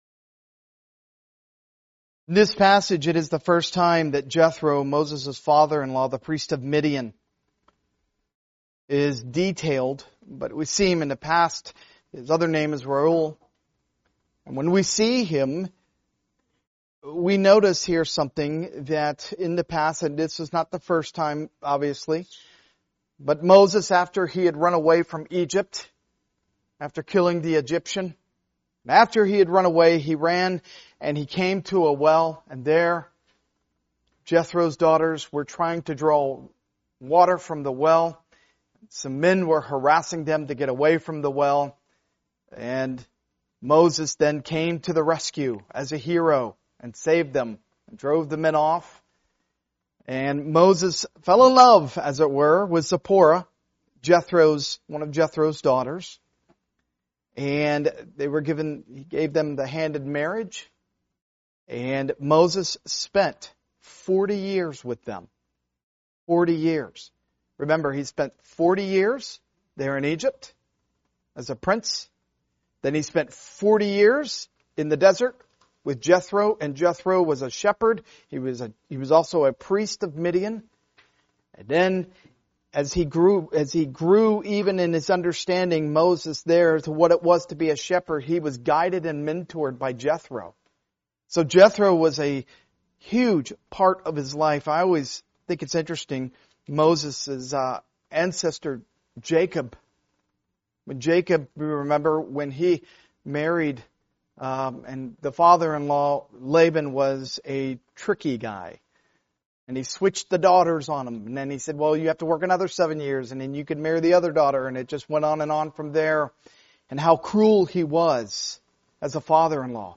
Part of the The Book of Exodus series, preached at a Morning Service service.